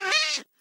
mob / cat / hit2.ogg